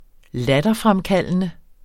Udtale [ ˈladʌˌfʁamˌkalˀənə ]